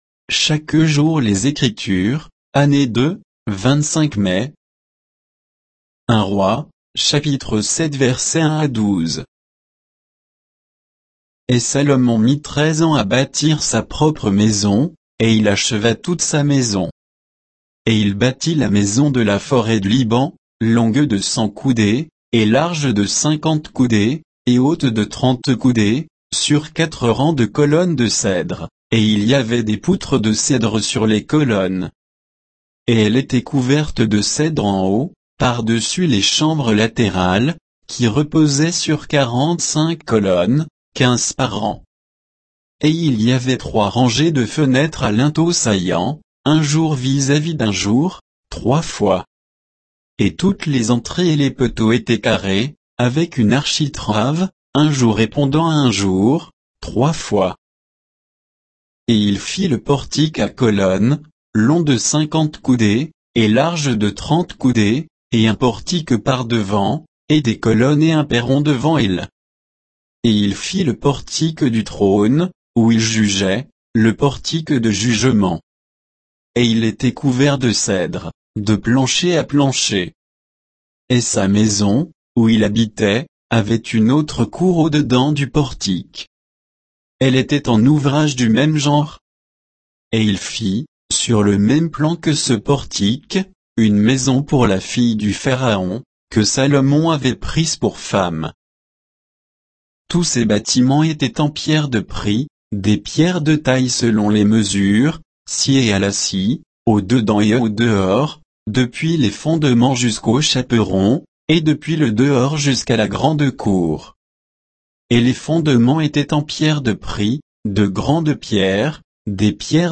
Méditation quoditienne de Chaque jour les Écritures sur 1 Rois 7, 1 à 12